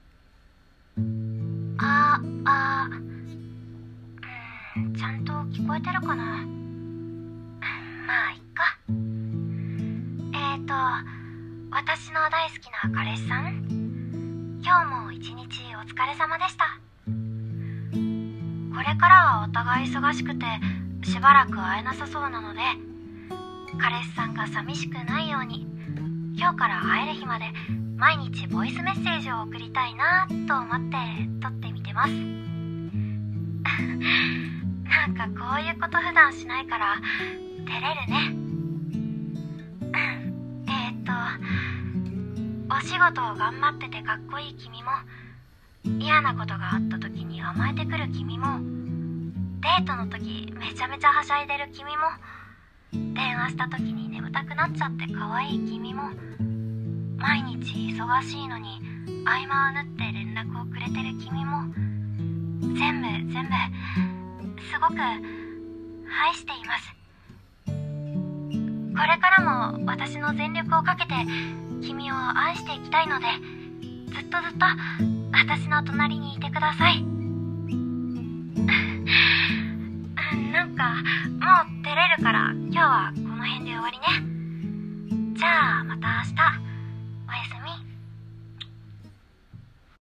【声劇】ボイスメッセージ
癒し